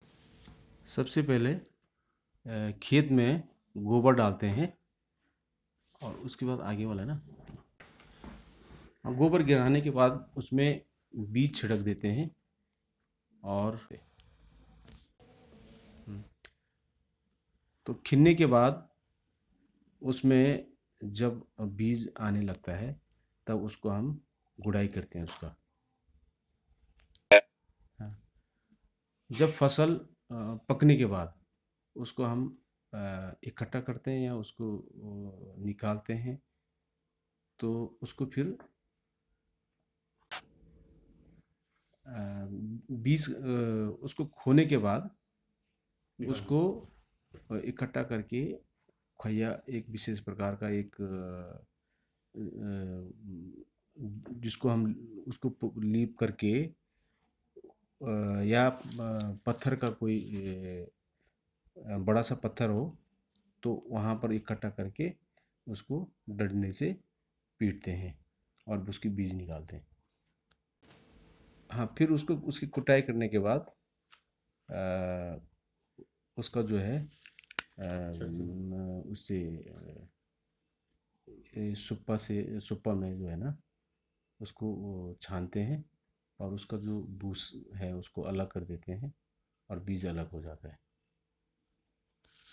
Retelling of a song in Rongpo